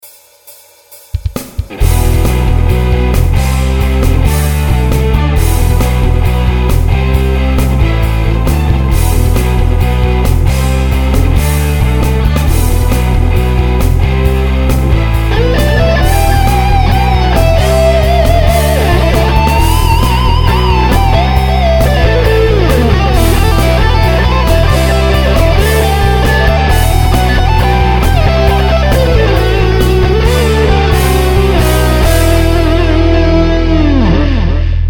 Nejako ma chytil homerecording, tak som zacal uplne od piky spoznavat cubase, battery, co su sample a samplery, ako to cele funguje, preguglil som asi 3hodiny cisteho casu a zistil ze Athlon 2000 je na velke projekty a vela amplitubov celkom malo.
po opakovanom prehrani tejto pol minuty mi to pripomina zatial nieco na styl after  forever.. kazopadne pekny solo zvuk.. ale ved to ty velmo dobre vies.. ocakavam nejake prekvapenie v tom songu..
hall na hihat ? si sa s konom zrazil ? nema to ziadny kopak. doprovodne gitary by som roztahal do stran a priostril. na solo menej dileju by som dal a tiez by som priostril. rytmicak tiez ostrejsi. aj syntaky maju vela halu, aj ten rytmicak. dokopy ako celok to potom nie je zrovna telocvicna, ale aj ked o dost krajsi priestor, tak prilis velky - kruti sa mi z toho hlava :)